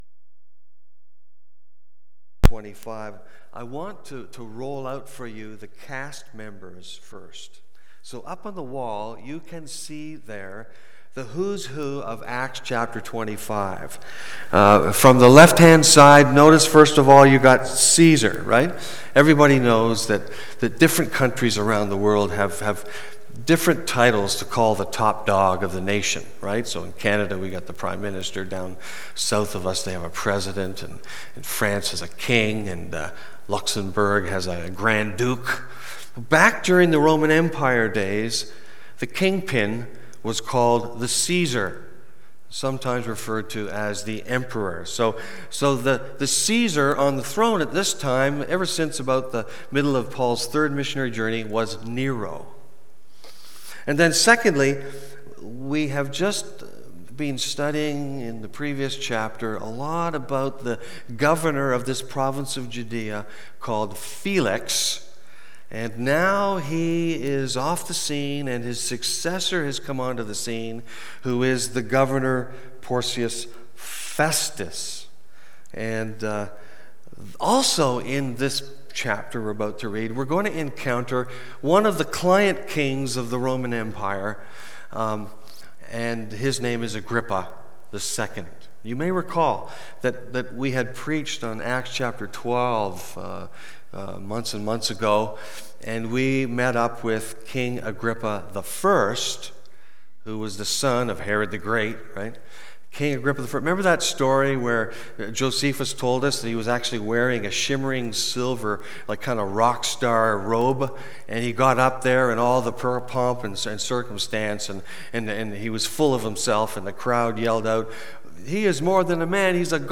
Part 69 BACK TO SERMON LIST Preacher